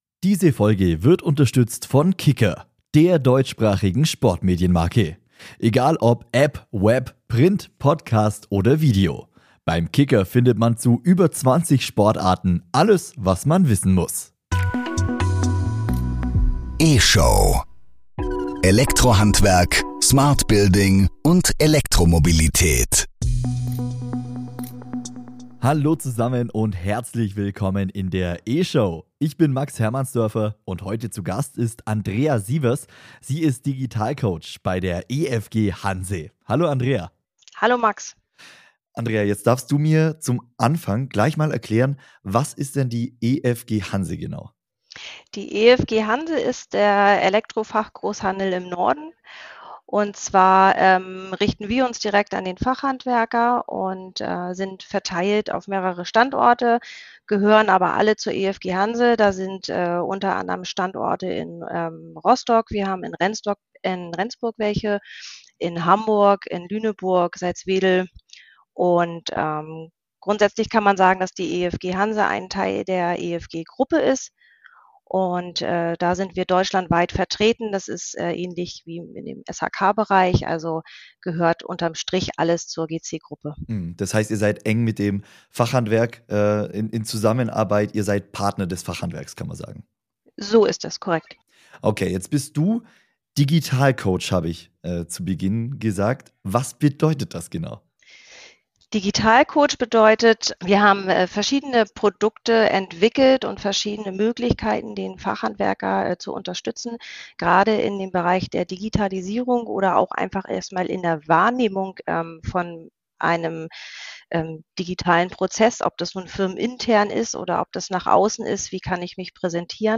Heute im Interview